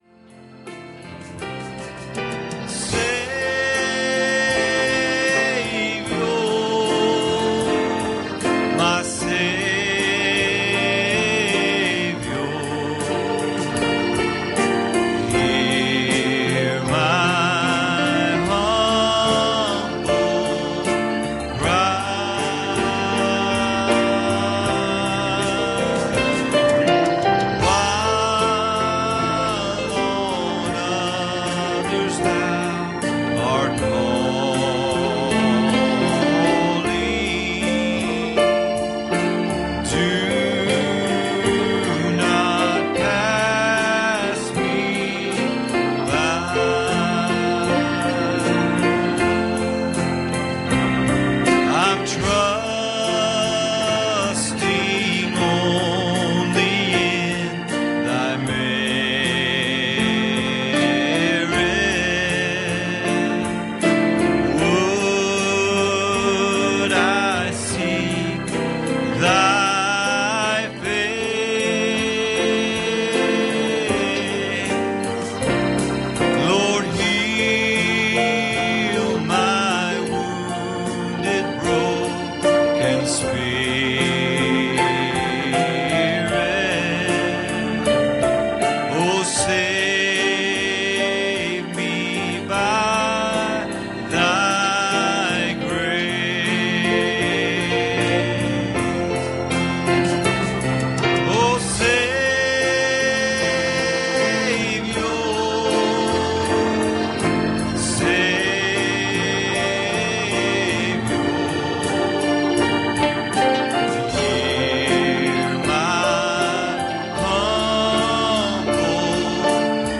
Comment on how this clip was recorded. Passage: Matthew 23:27 Service Type: Wednesday Evening